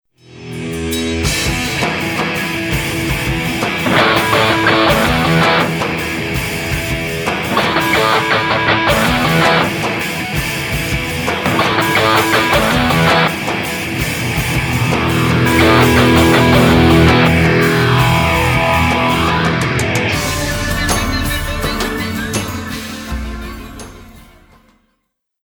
Isolated lead guitar part